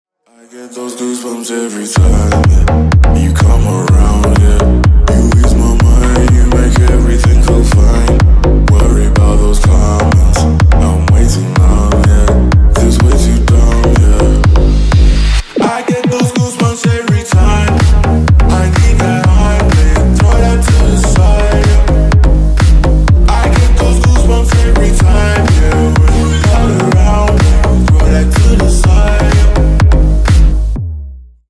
Ремикс # Танцевальные
клубные